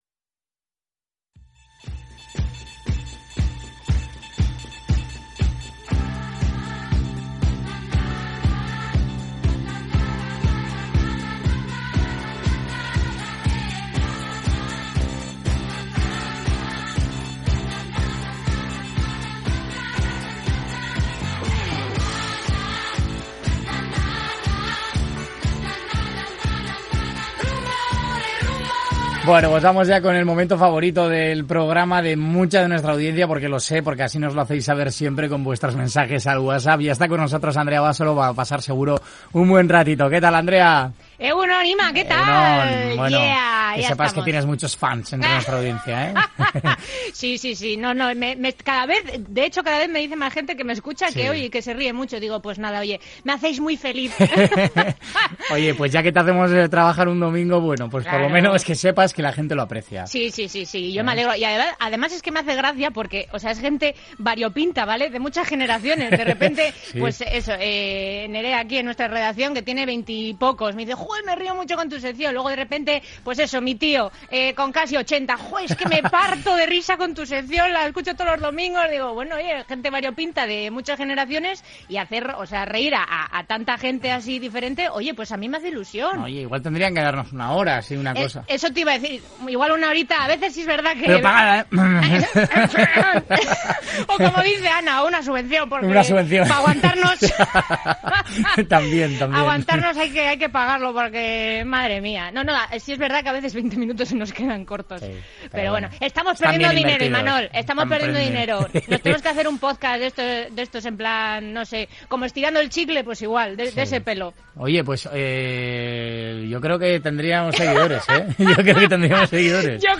Entre una cosa y otra, hemos pasado un ratito la mar de divertido charlando de las cosas de la vida, como nos gusta.